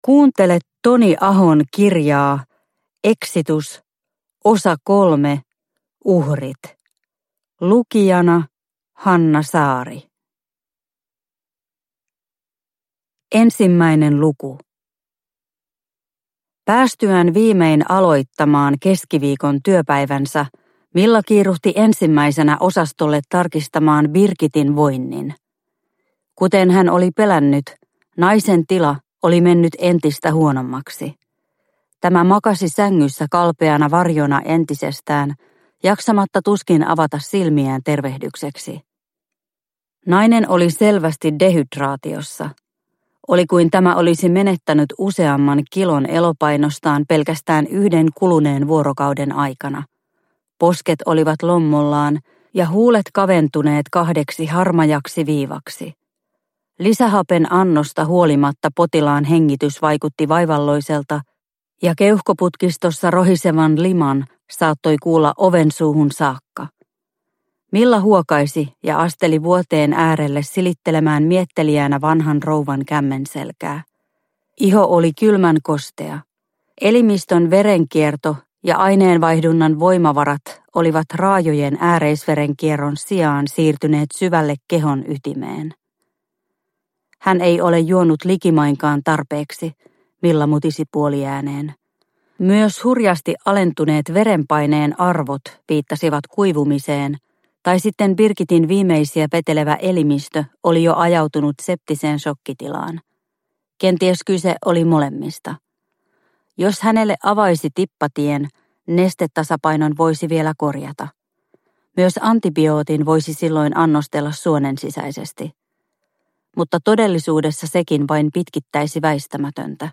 Exitus – Ljudbok – Laddas ner